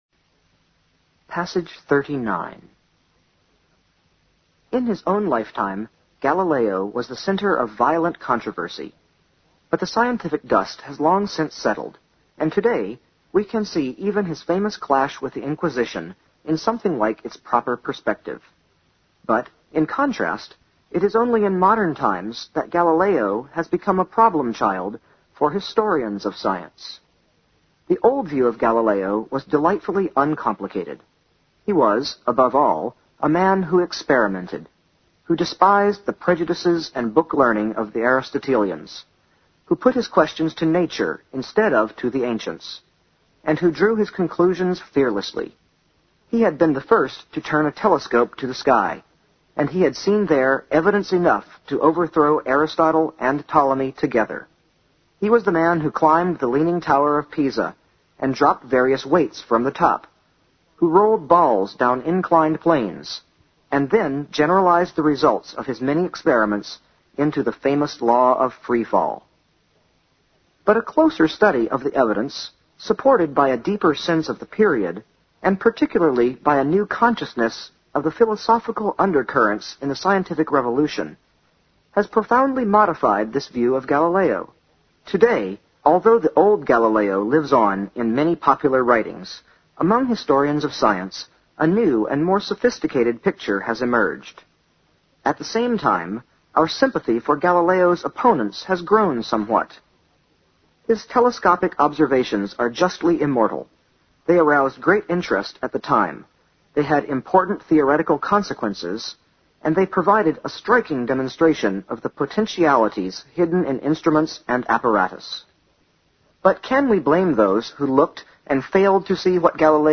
新概念英语85年上外美音版第四册 第39课 听力文件下载—在线英语听力室